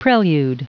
Prononciation du mot prelude en anglais (fichier audio)
Prononciation du mot : prelude